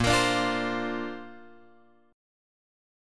A#add9 chord {6 x 8 7 6 8} chord